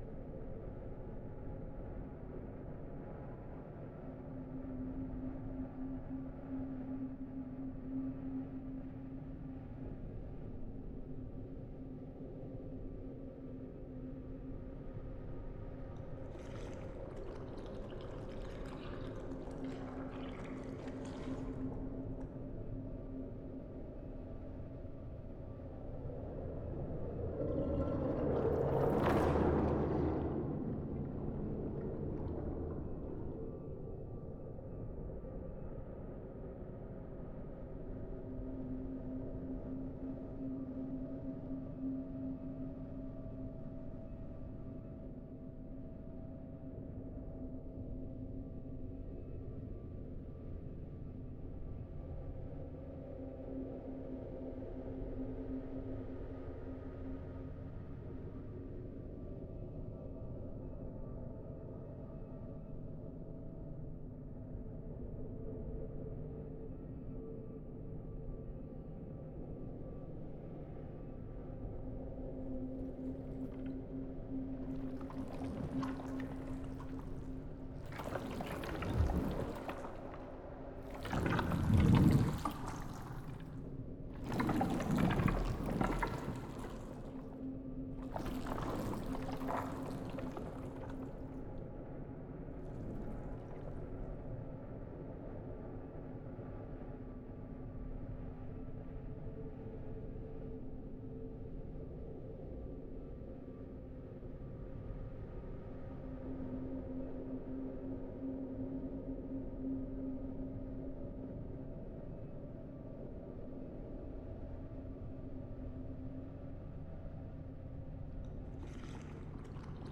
muddy-ooze-dungeon.ogg